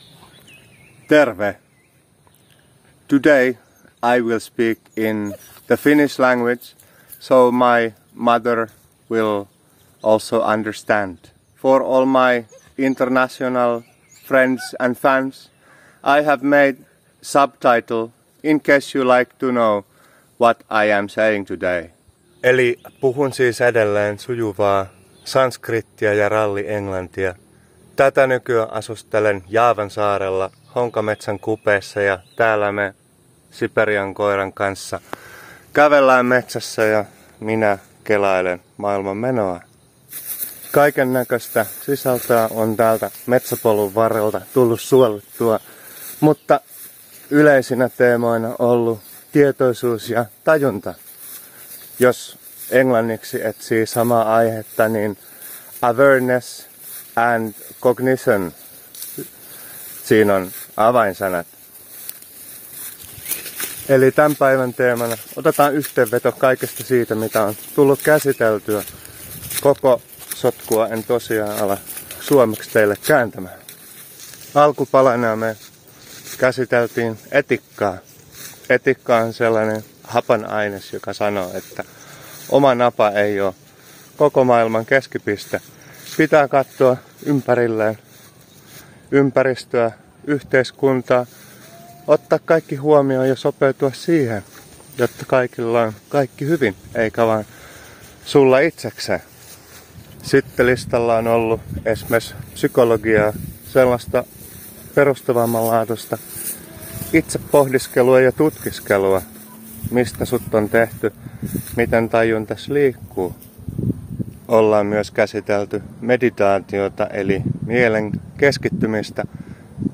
On the forest trail, I and the dog reel up the stuff of life.